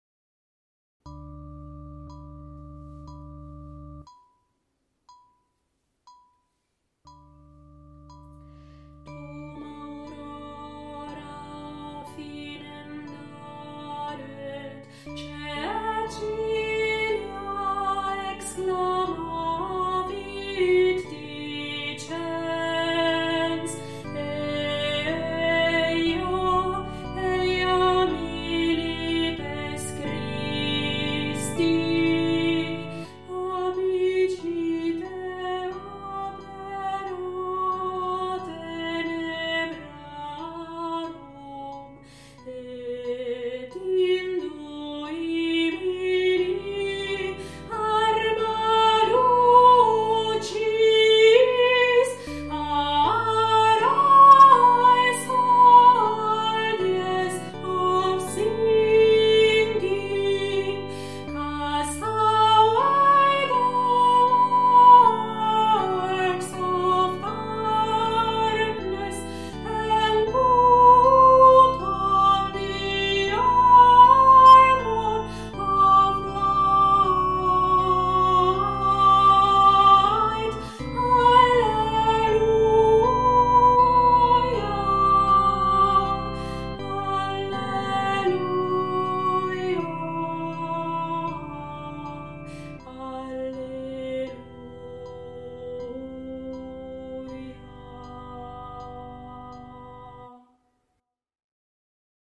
ALTO - Dum Aurora